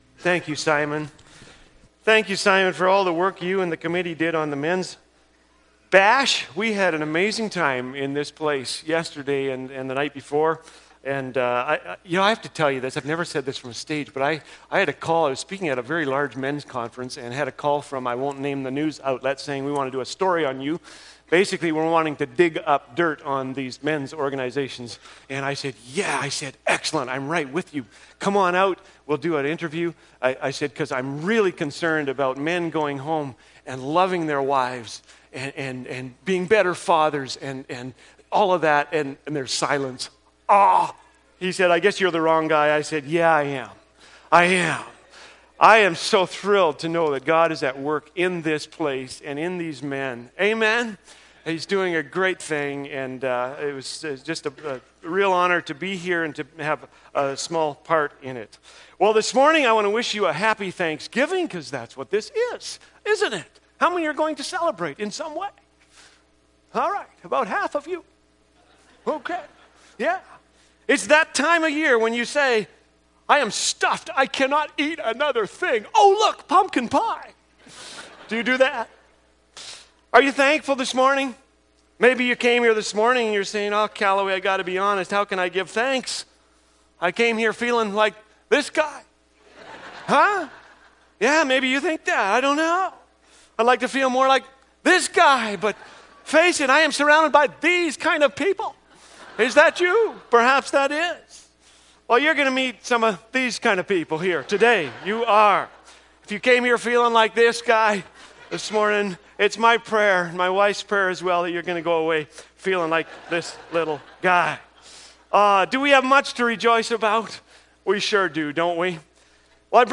Series: Guest Preachers